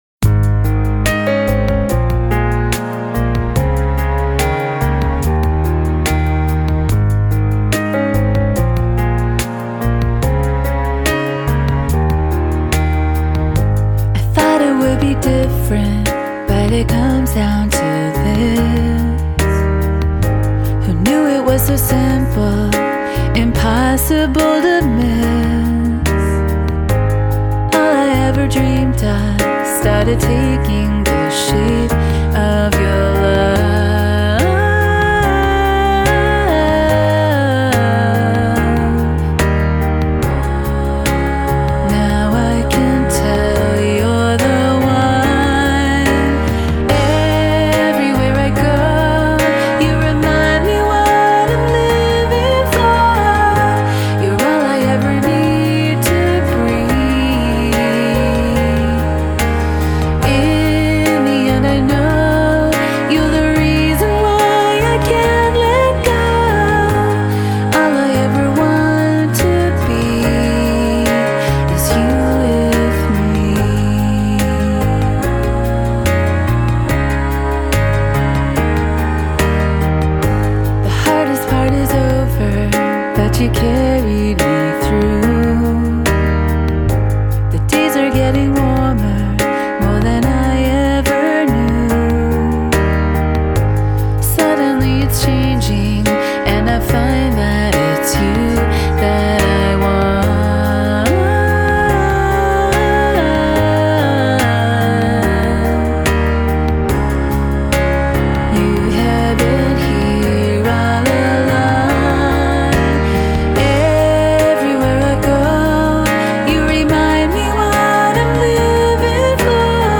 Одаренная и талантливая композитор и исполнитель из США.
Genre: Pop, Folk, Rock